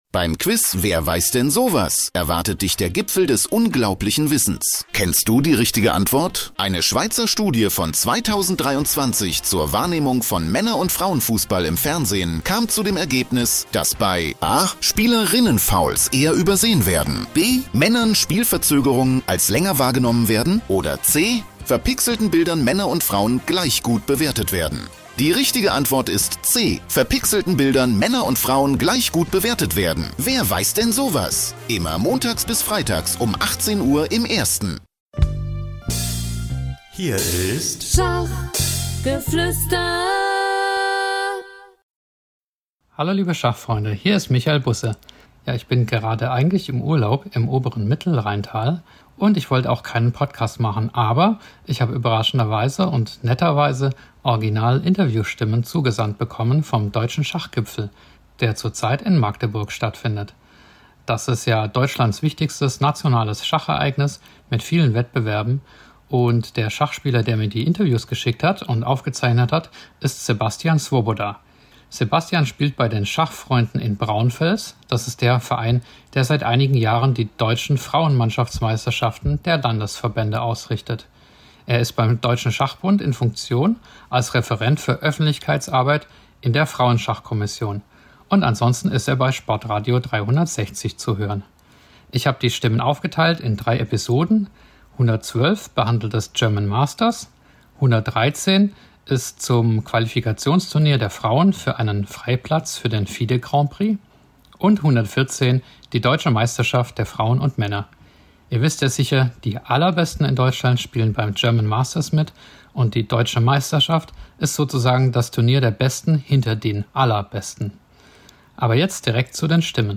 Original Stimmen von Deutschlands Besten!